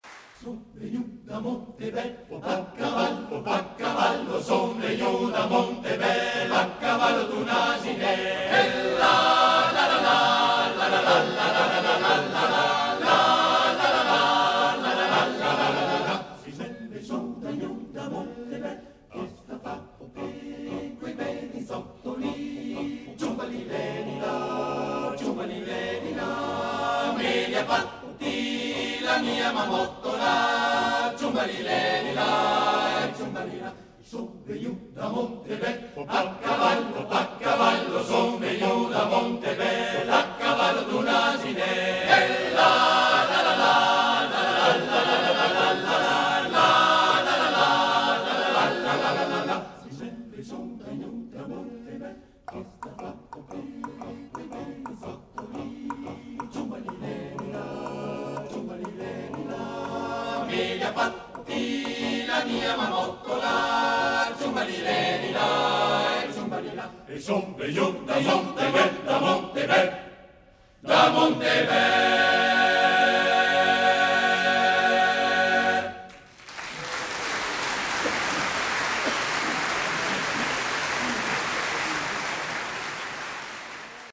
Danza paesana (da Montebel) : Canto popolare trentino / F. Sartori [armonizzatore]
Arrangiatore: Sartori, Franco <1892-1965> (armonizzatore)
Esecutore: Coro Trentino SOSAT